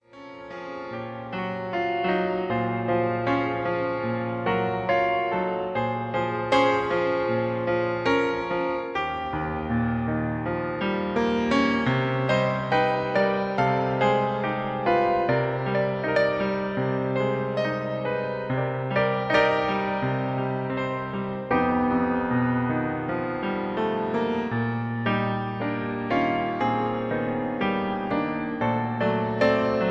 (Key-Ab)